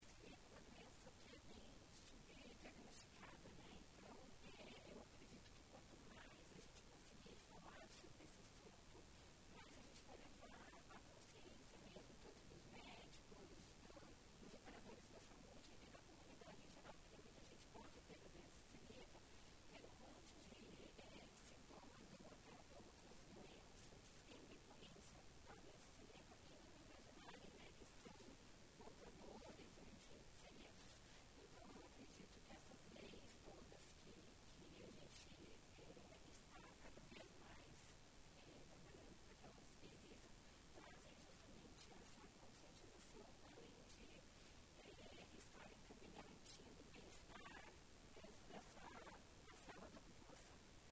Ciclo de palestra aconteceu na manhã desta quinta-feira (11), no Auditório Legislativo.